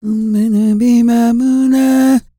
E-CROON 3040.wav